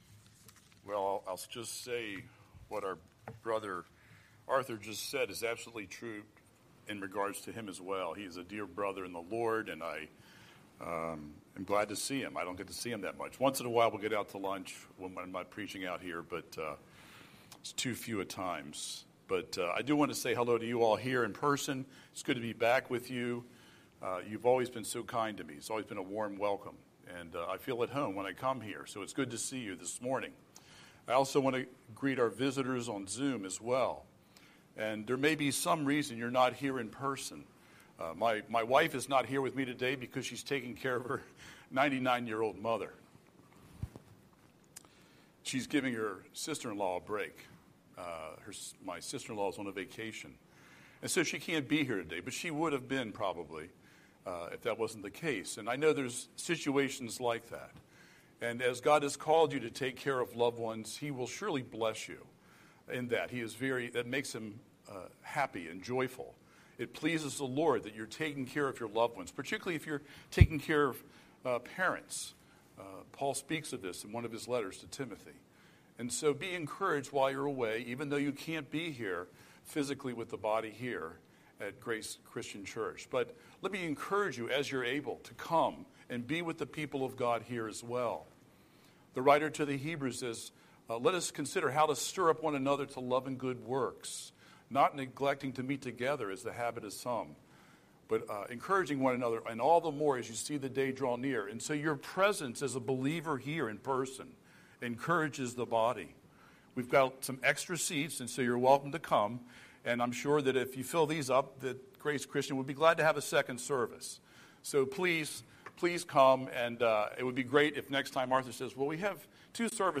Scripture: John 20:19-23 Series: Sunday Sermon